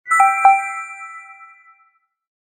Game Success Sound Effect
Positive game sound effect for hits, scores, bonuses, new life, correct answers, or winning moves. Perfect for games, apps, and videos.
Game-success-sound-effect.mp3